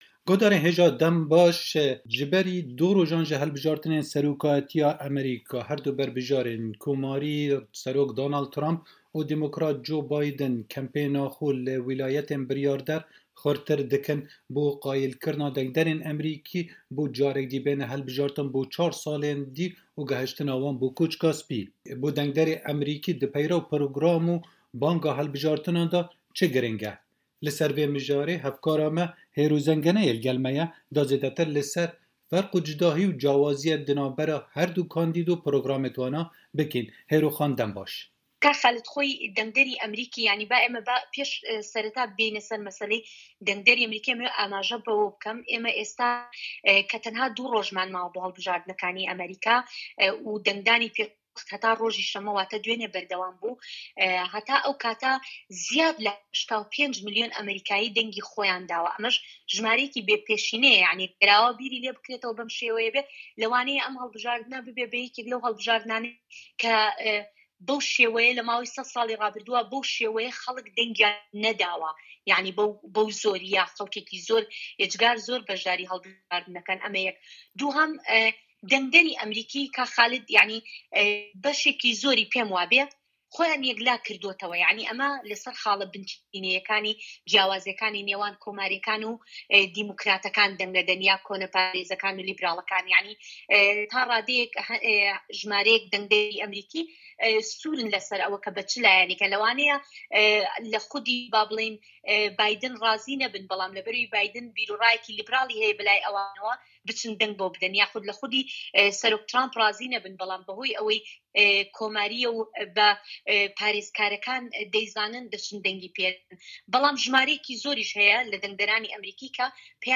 وتووێژێکی تایبەت لەسەر هەڵبژاردنەکانی سەرۆکایەتی ئەمریکا